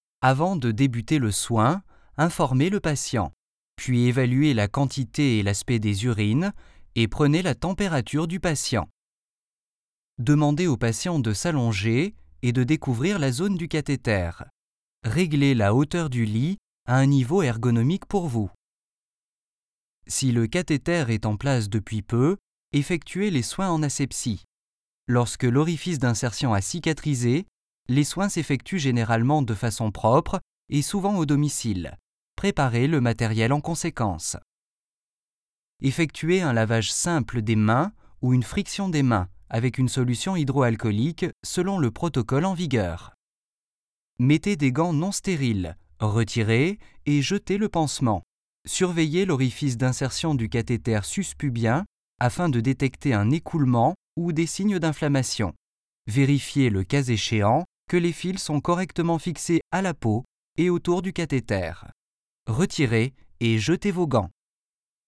Male
Conversational, Cool, Corporate, Energetic, Friendly, Natural, Soft, Streetwise, Upbeat, Warm, Young
Commercial.mp3
Microphone: Manley Reference Cardioid
Audio equipment: Soundbooth Demvox / Preamp Manley Core / Digital interface RME Fireface UC / Cables Vovox